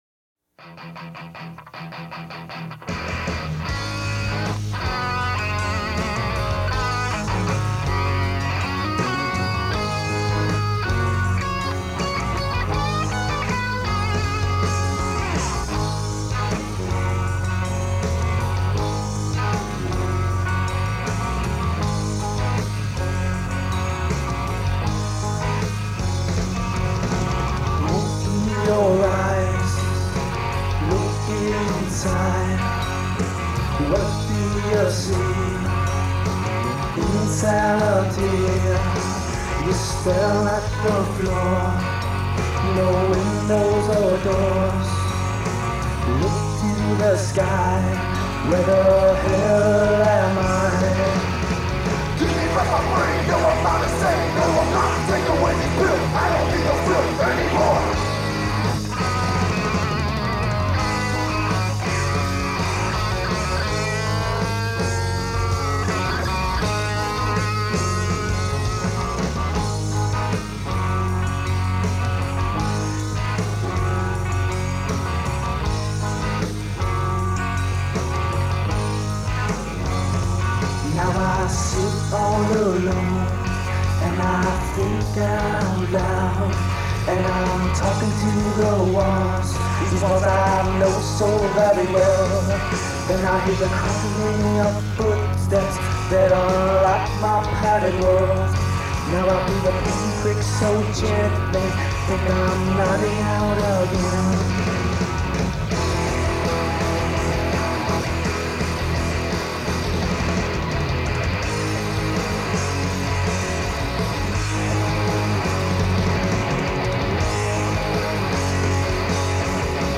Guitar and Vocals
Bass
Drums